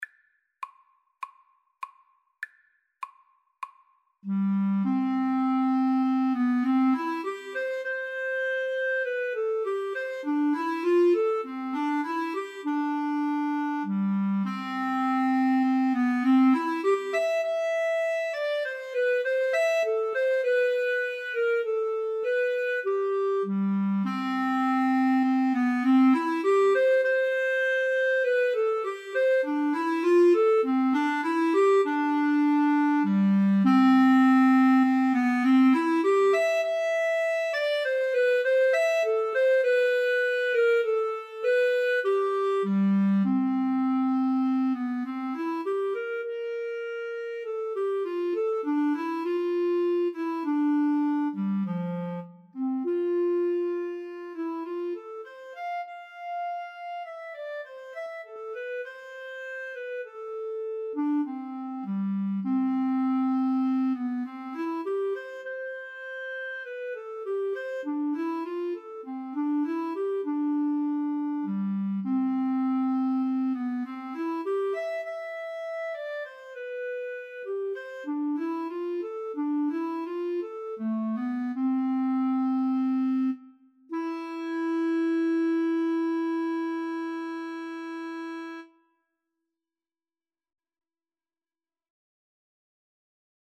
Classical (View more Classical Clarinet-Cello Duet Music)